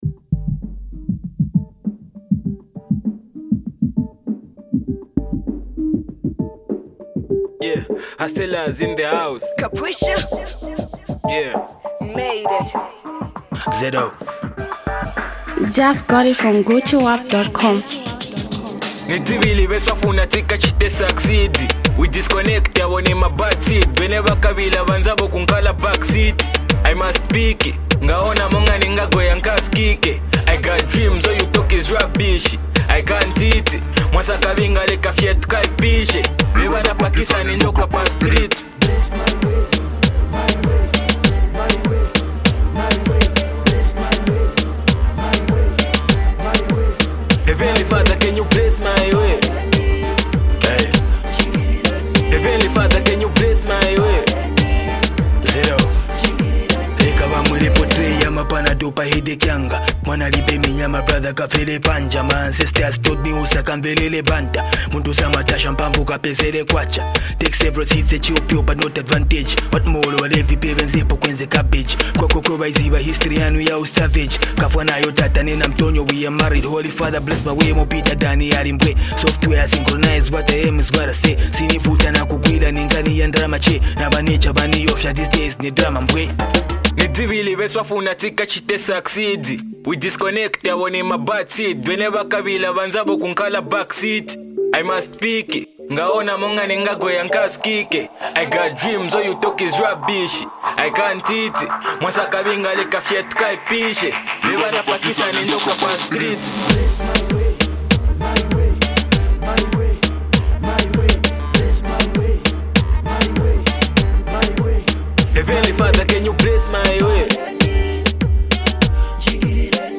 rapper and songwriter